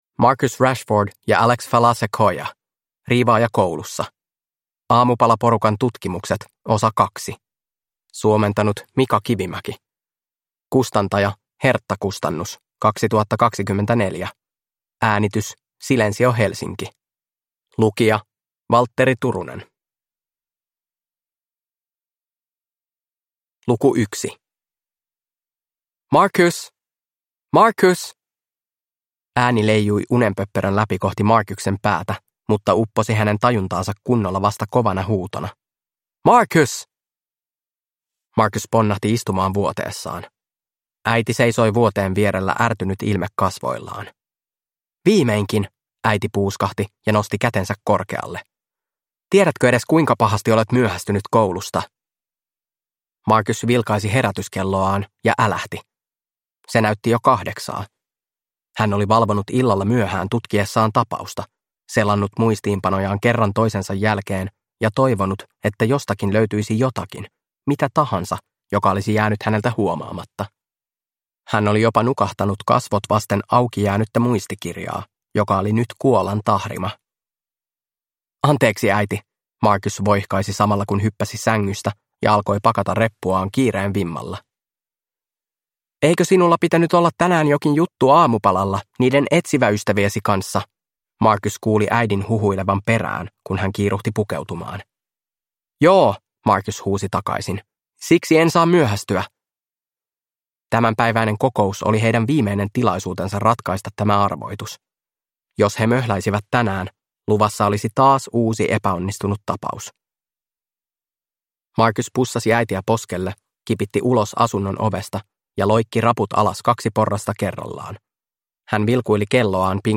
Riivaaja koulussa – Ljudbok